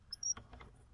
描述：一种短促的吱吱声。索尼PCMD50